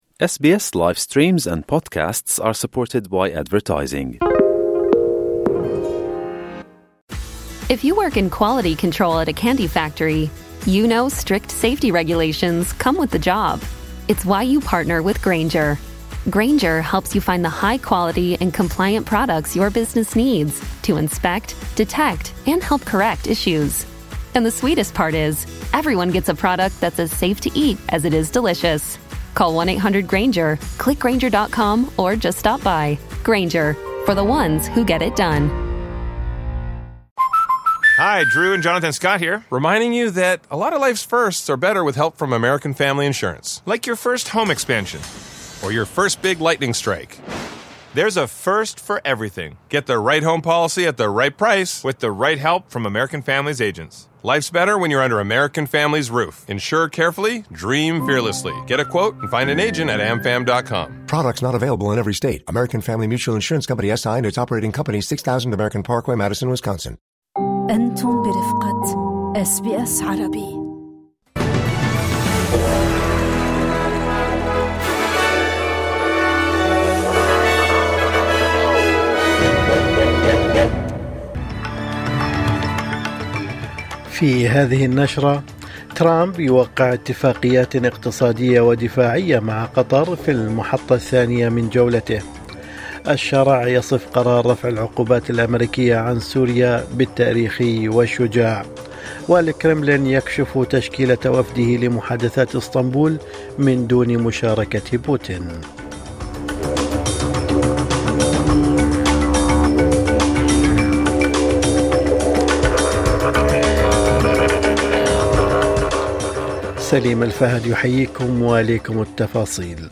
نشرة أخبار الصباح 15/5/2025